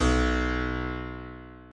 Harpsicord
b1.mp3